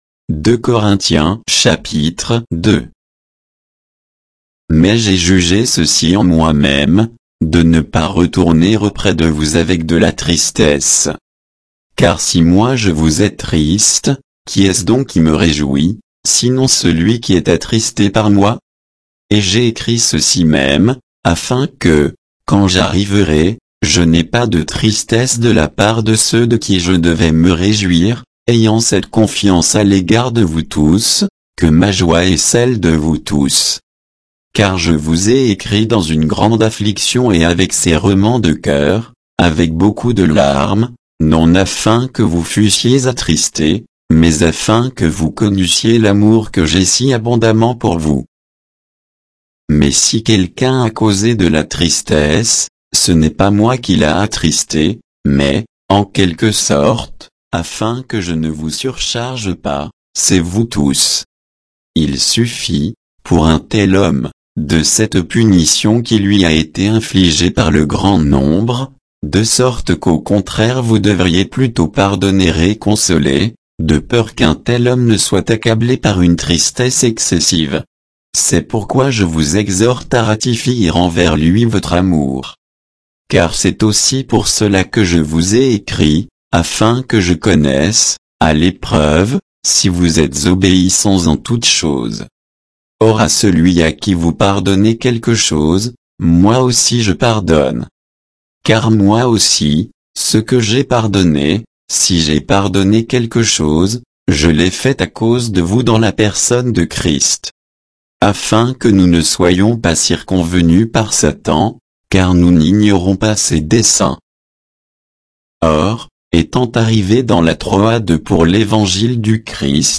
Bible_2_Corinthiens_2_(texte_uniquement).mp3